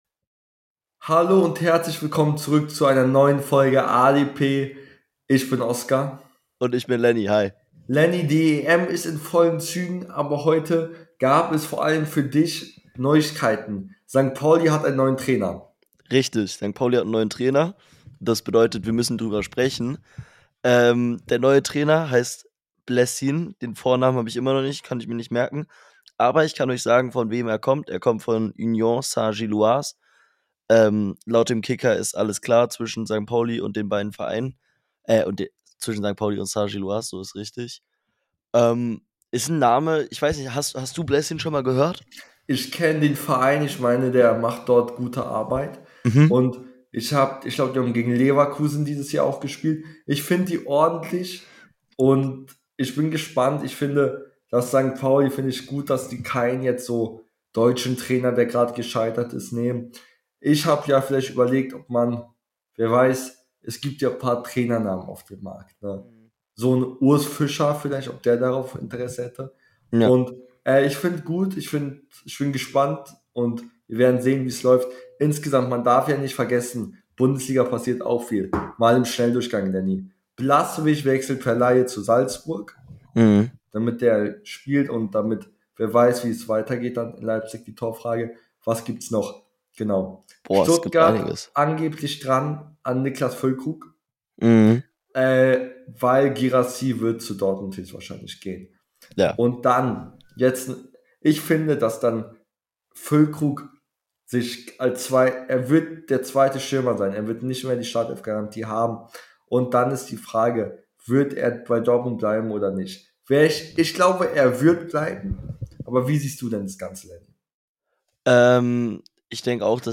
In der heutigen Folge reden die beiden Hosts über Bayerns Umbruch,Paulis neuen Trainer , die EM und vieles mehr